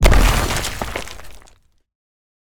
building_explosion.ogg